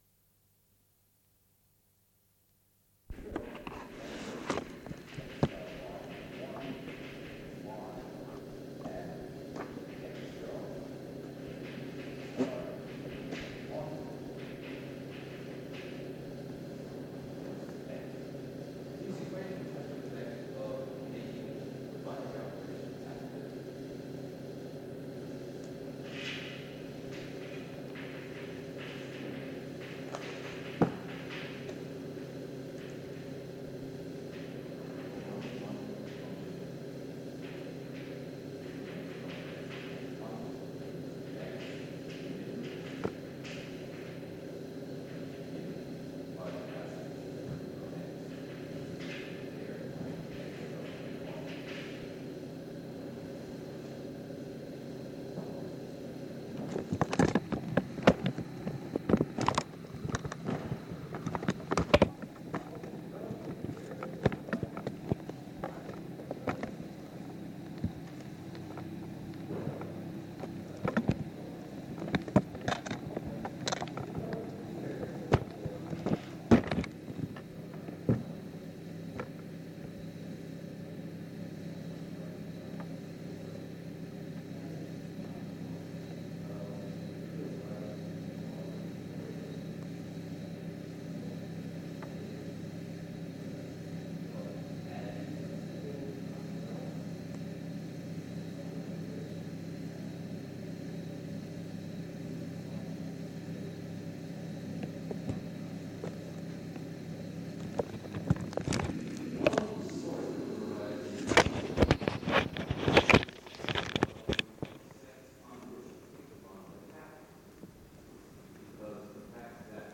XX/08/78 Conversations re aspects of Topoos theory - Archive Trust for Research in Mathematical Sciences & Philosophy